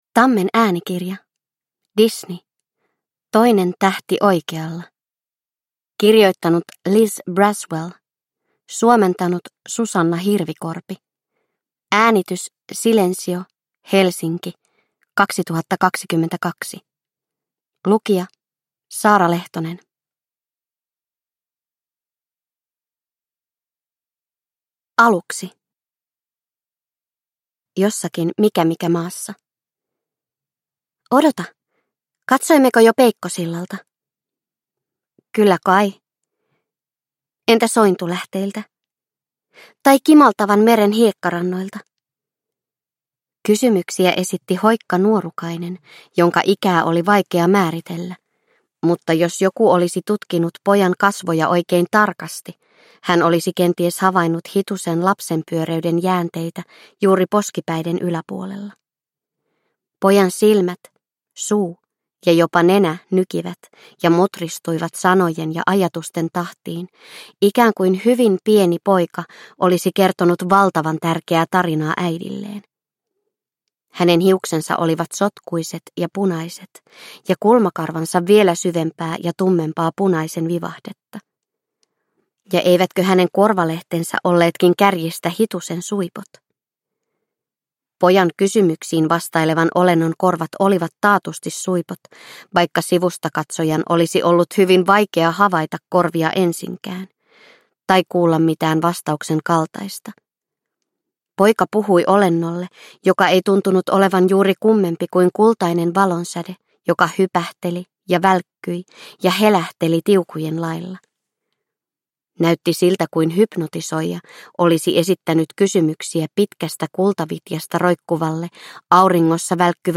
Toinen tähti oikealla. Twisted Tales – Ljudbok – Laddas ner